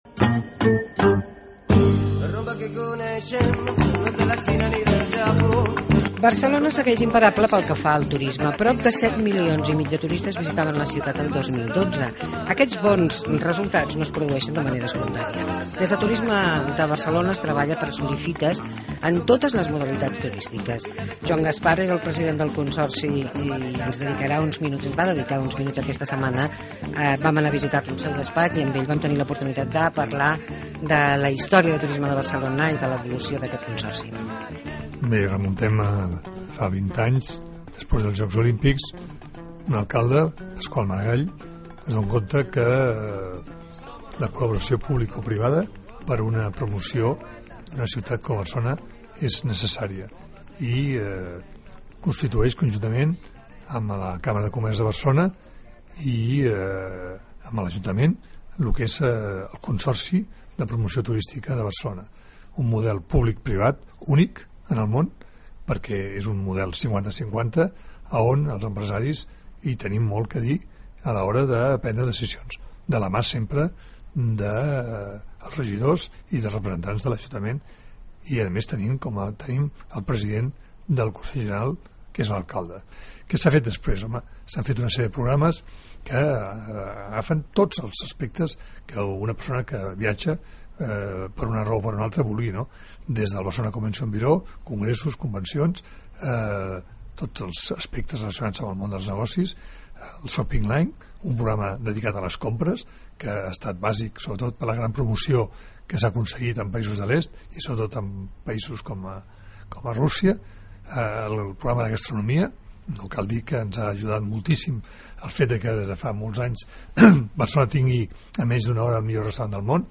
Turisme de Barcelona. Entrevista amb Joan Gaspart